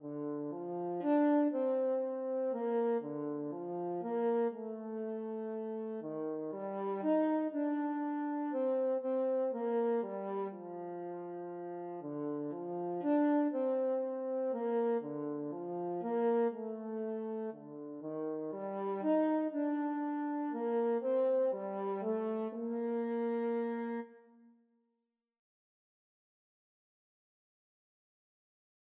Traditional Trad. Rock-A-Bye, Baby French Horn version
3/4 (View more 3/4 Music)
Gently = c.120
E4-F5
C major (Sounding Pitch) G major (French Horn in F) (View more C major Music for French Horn )
French Horn  (View more Beginners French Horn Music)
Traditional (View more Traditional French Horn Music)